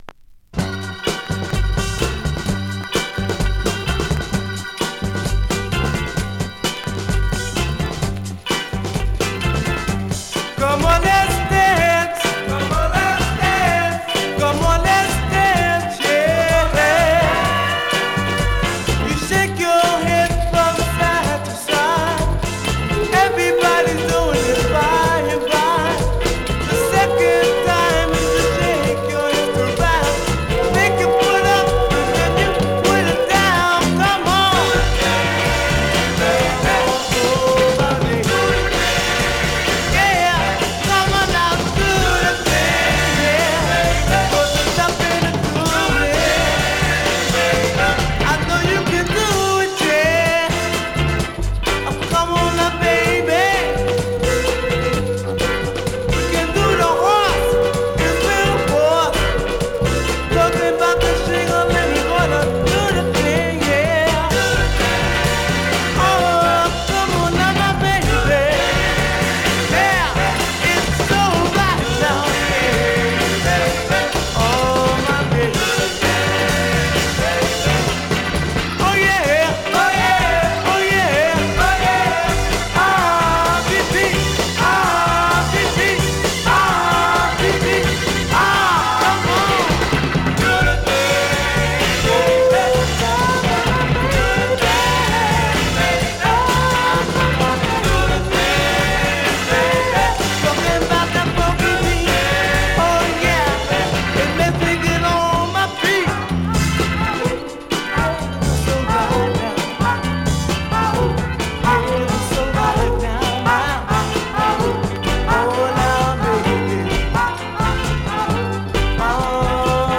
Vinyl has a few very light marks plays great .
Great mid-tempo Northern / Rnb dancer .
R&B, MOD, POPCORN , SOUL